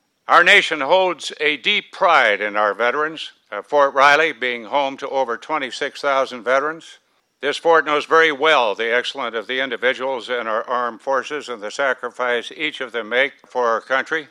FORT RILEY – A Memorial Day ceremony honored fallen soldiers Monday at the Fort Riley post cemetery.
Roberts took time to address the sacrifices made by service men and women.